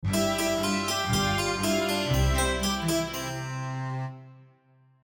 End with Cembalo